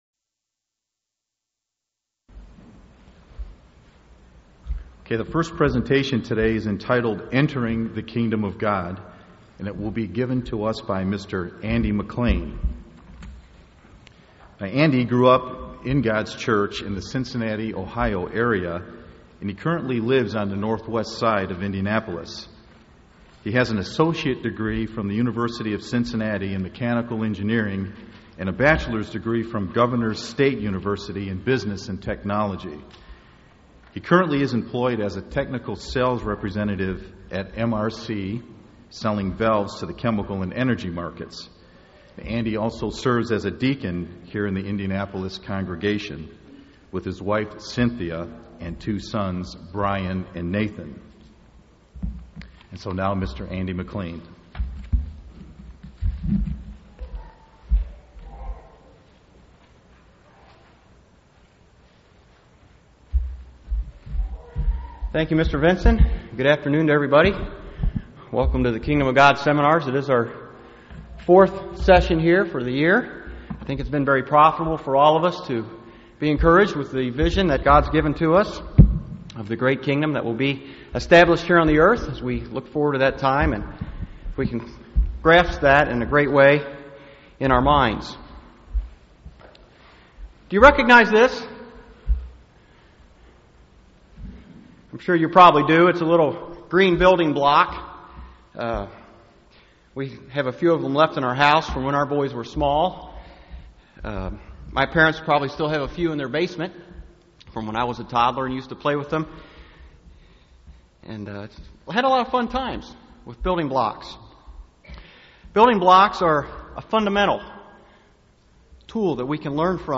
Kingdom of God Bible Seminar. God wants us to become a part of His family in His Kingdom, but there are fundamental requirements for entering the Kingdom of God.
UCG Sermon Studying the bible?